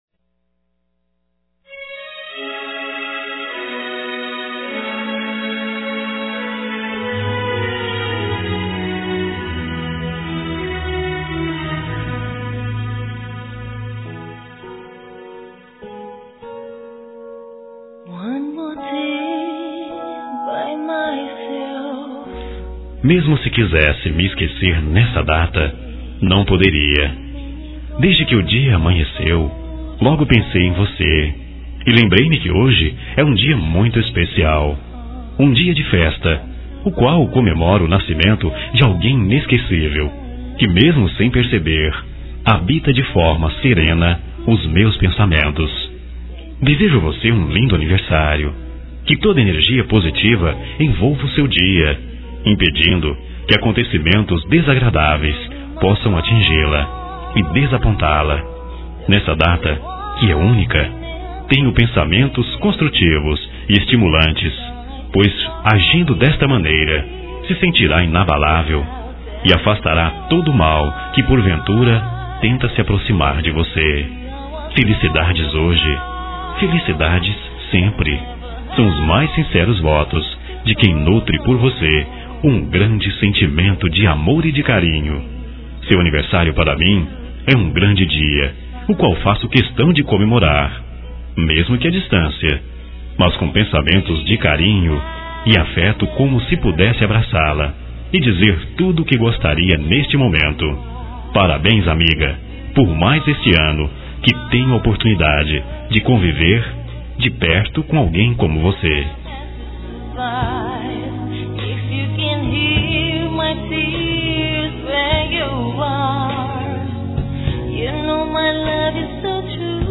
Telemensagem Aniversário de Paquera -Voz Masculina – Cód: 1274